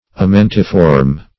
Amentiform \A*men"ti*form\, a.